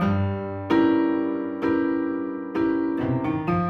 Index of /musicradar/gangster-sting-samples/130bpm Loops
GS_Piano_130-G2.wav